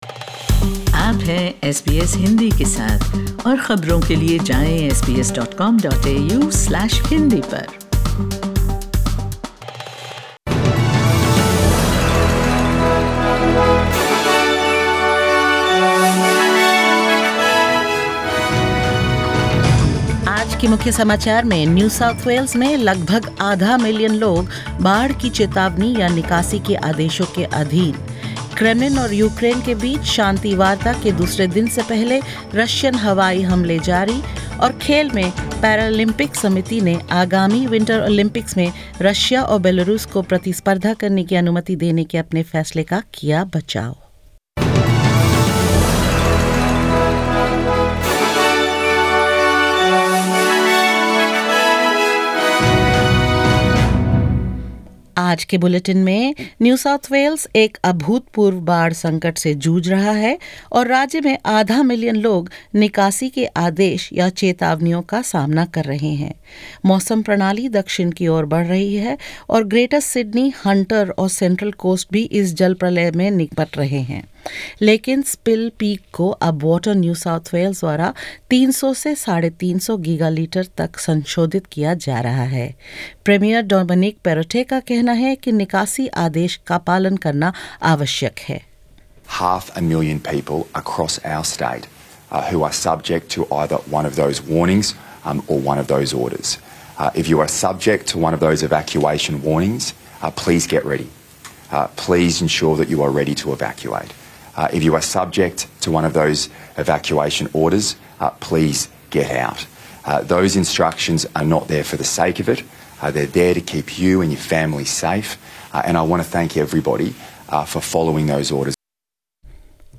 In this latest SBS Hindi bulletin: New South Wales Premier Dominic Perrottet says half a million people across the state are subject to flood warnings or evacuation orders; Airstrikes continue before the second round of peace talks resume between Russia and Ukraine; The Paralympic Committee defends its decision to allow Russian and Belarusian athletes to compete in the upcoming Winter Games and more.